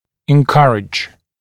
[ɪn’kʌrɪʤ] [en-][ин’каридж] [эн-]поощрять, поддерживать, одобрять